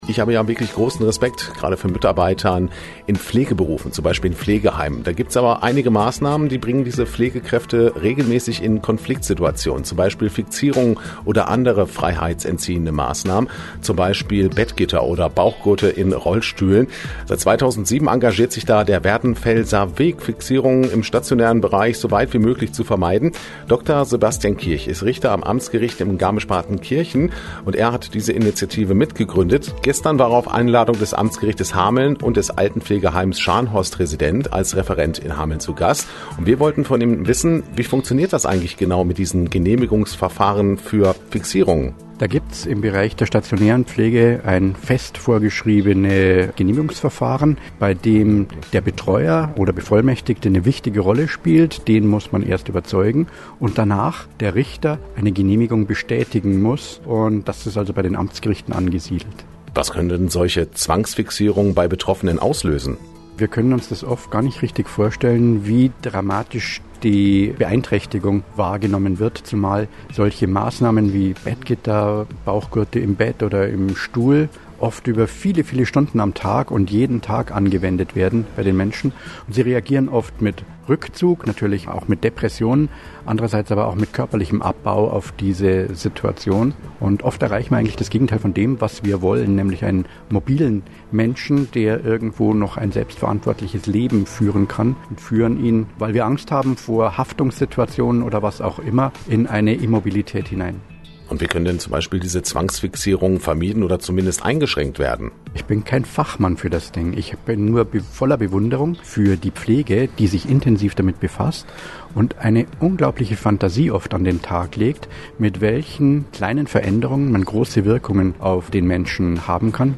Radiointerview abrufbar.